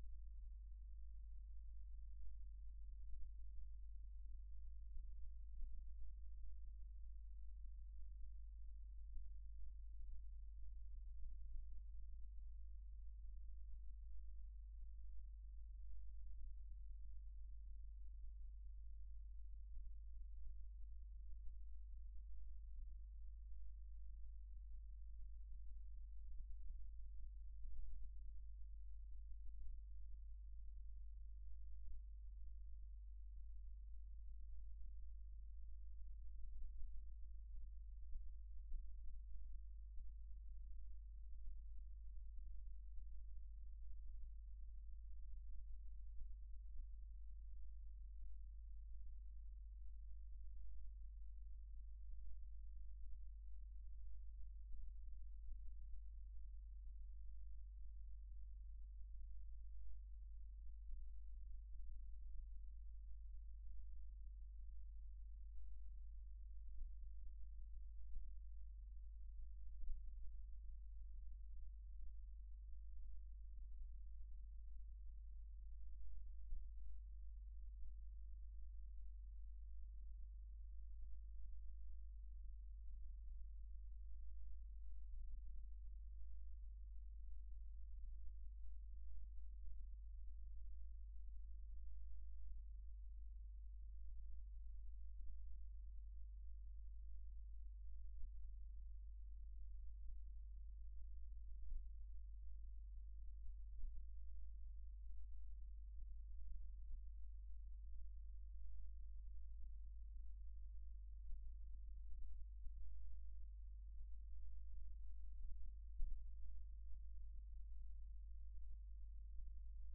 Geomagnetic storm with many pulsations PC1
From Medicina (IT - Bologna), N Italy Signal detected with ULFO induction coil.